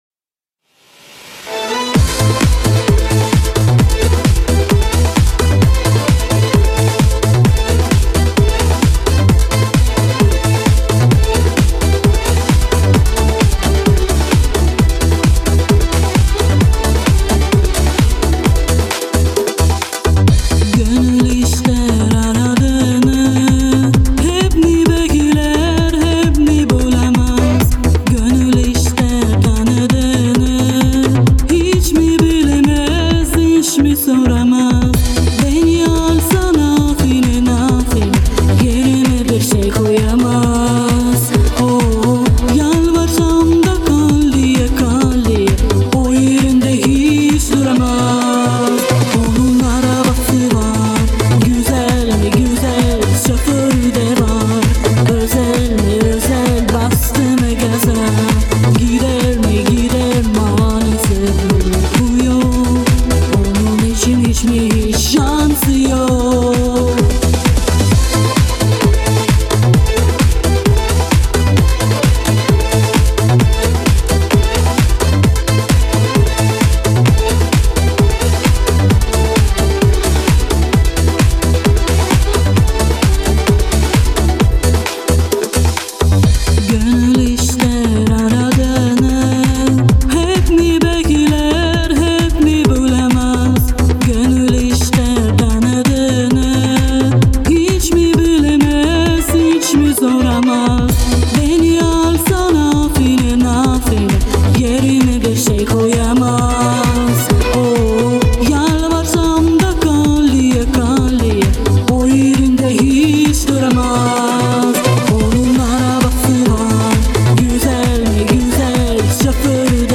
исполнение, бэки, вокализ-импровизация
мужской речитатив